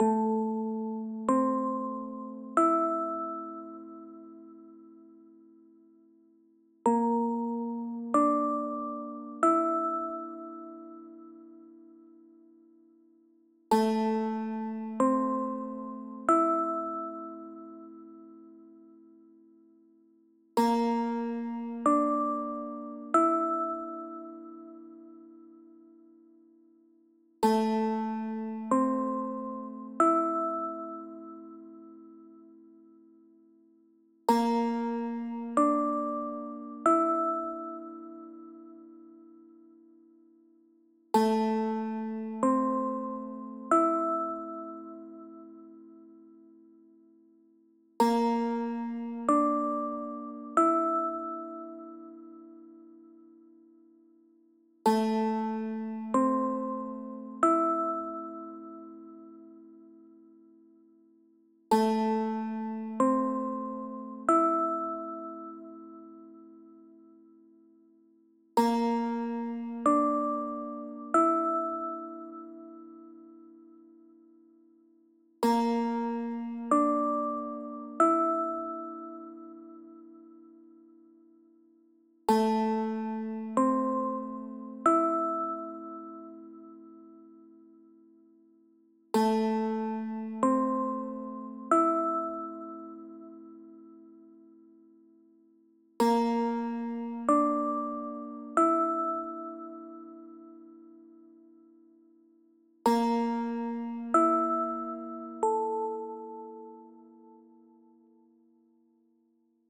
ループ：◎
BPM：35 キー：Am ジャンル：ゆったり 楽器：オルゴール